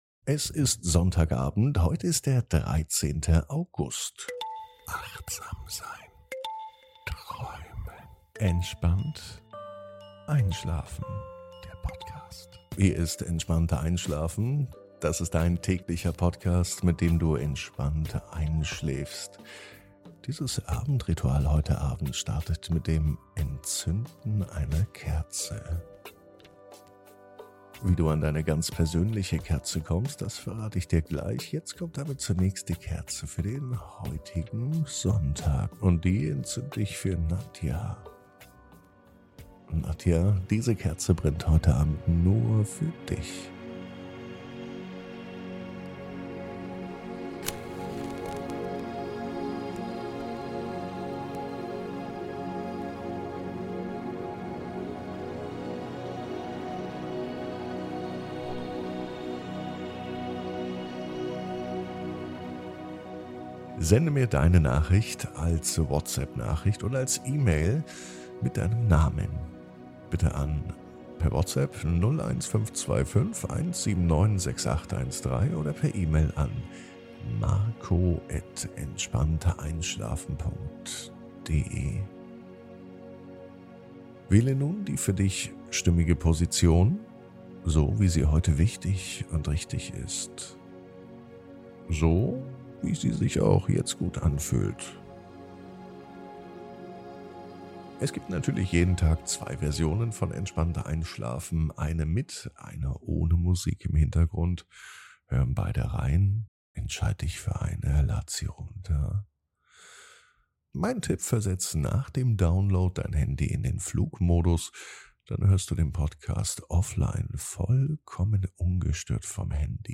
(ohne Musik) Entspannt einschlafen am Sonntag, 13.08.23 ~ Entspannt einschlafen - Meditation & Achtsamkeit für die Nacht Podcast